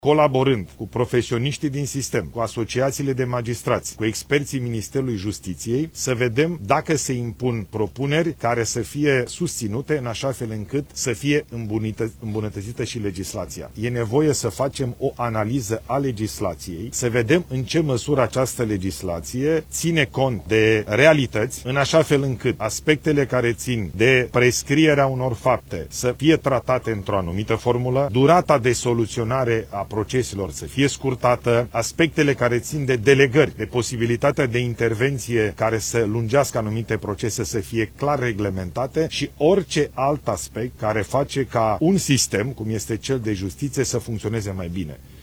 Prim-ministrul Ilie Bolojan: „E nevoie să facem o analiză a legislației, să vedem în ce măsură această legislație ține cont de realități”